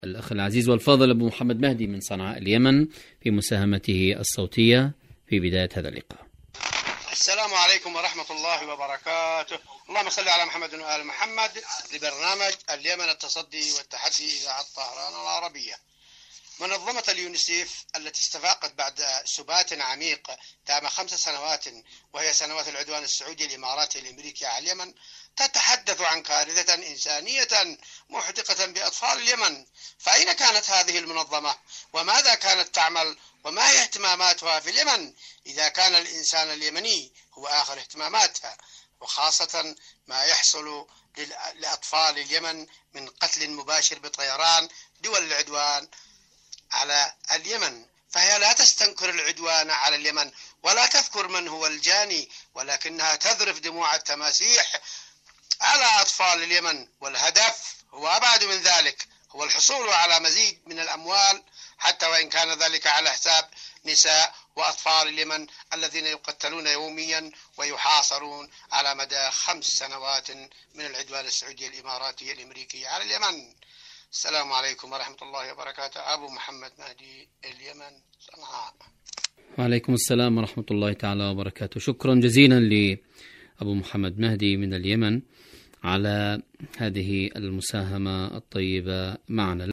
مشاركة واتساب صوتية